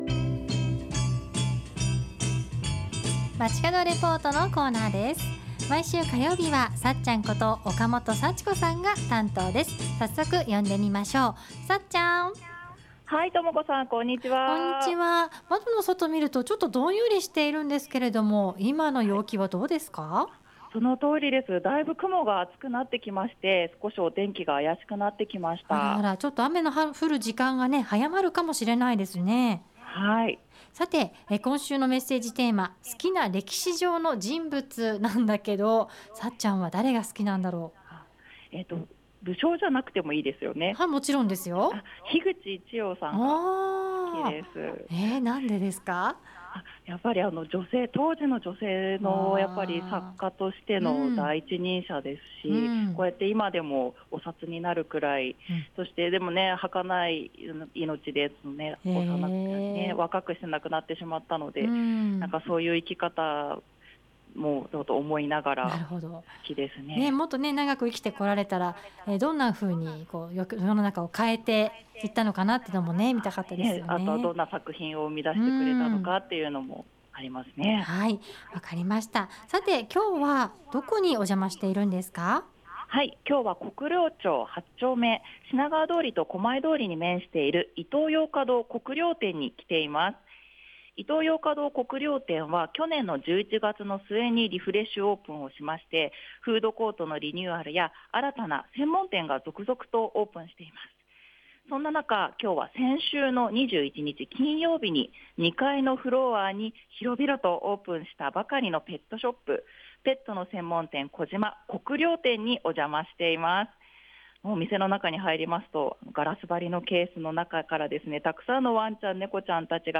今日は国領町8丁目にある「イトーヨーカドー国領店」2階のフロアに 先週の2月21日（金）にオープンしたばかりのペットショップ「ペットの専門店コジマ 国領店」から中継しました。